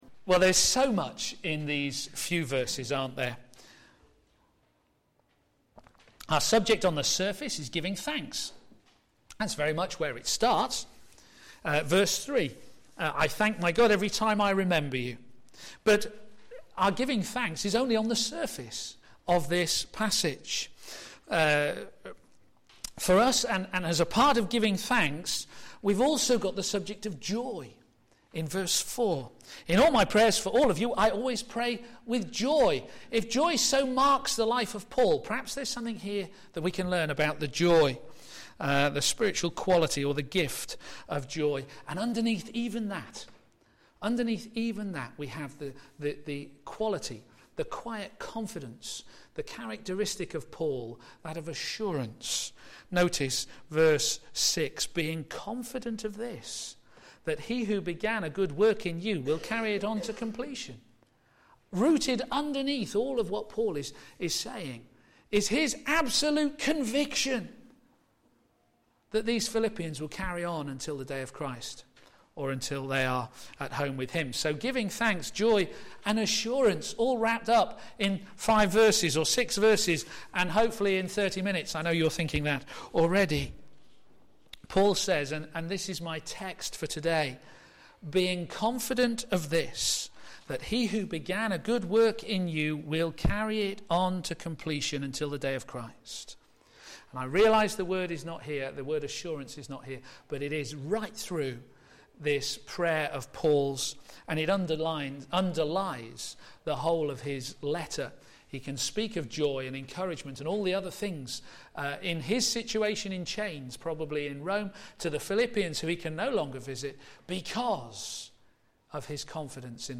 a.m. Service on Sun 14th Oct 2012 10:30
Living the life of Joy: Giving Thanks Sermon